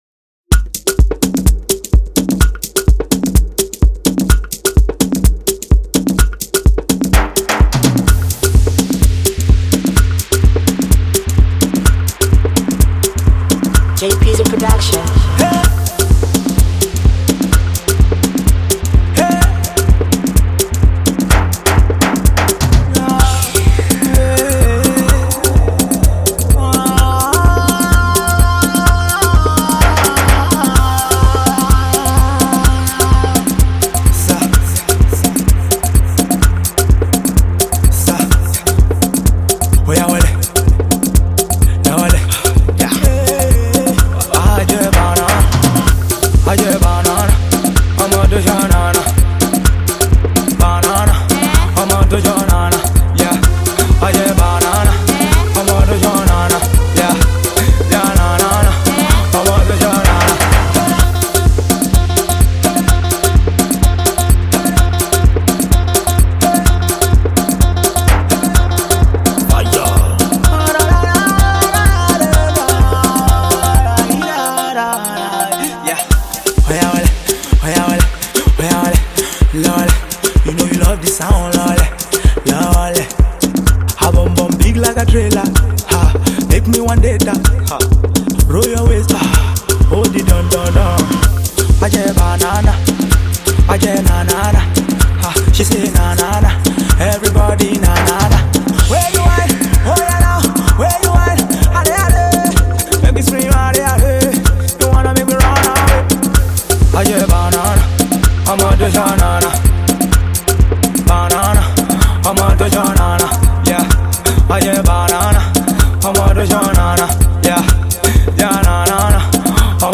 gives the Nigerian club scene a new smash